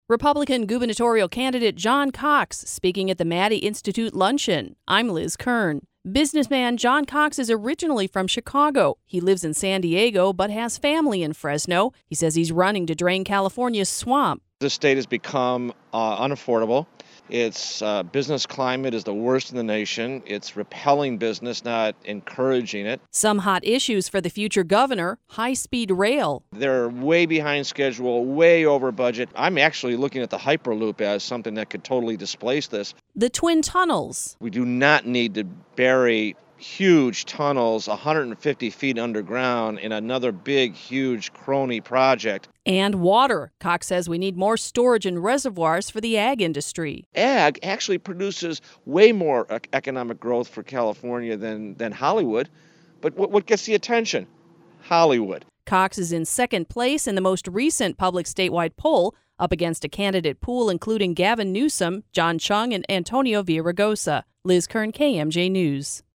FRESNO, CA (KMJ) – Republican gubernatorial candidate John Cox speaks Tuesday at the Maddy Institute Luncheon.
Click below to listen to the report by KMJ’s